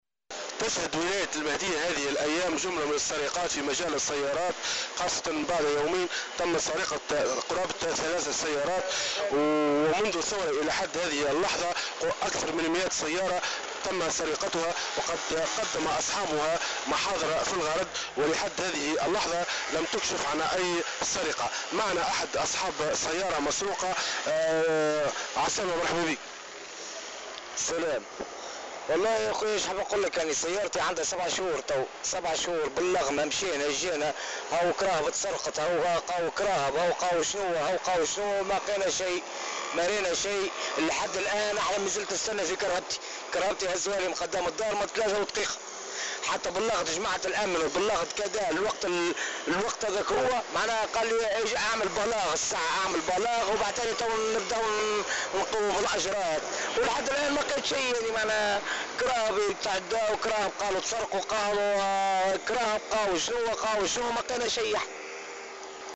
Au micro de Jawhara FM, l’une de ces victimes a affirmé jeudi 3 juillet 2014 qu’il n’a reçu aucune nouvelle de son véhicule volé depuis plus de 7 mois malgré qu’il a signalé le vol aux autorités sécuritaires.